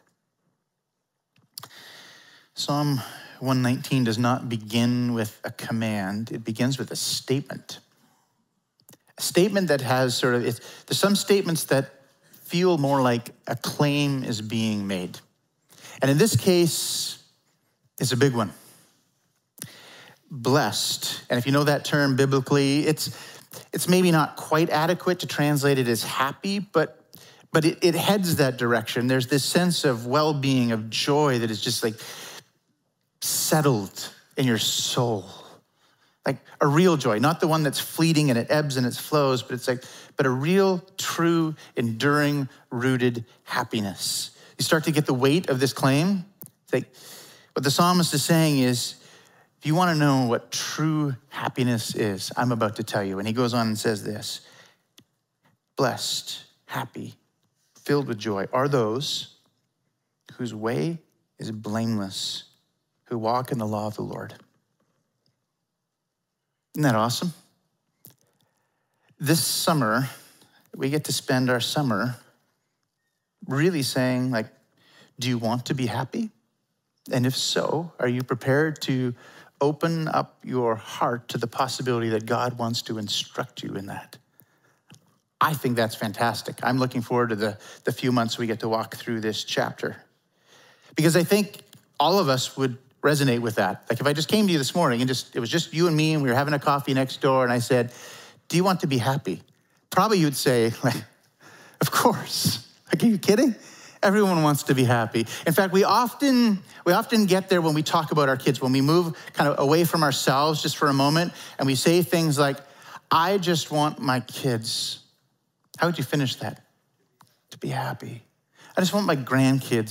Sermons | Emmanuel Baptist Church